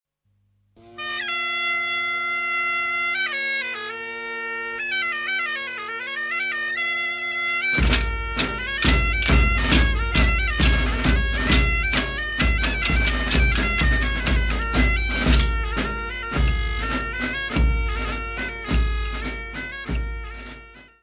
Folclore português : Trás-os-Montes e Alto Douro
Grupo Folclórico Mirandês de Duas Igrejas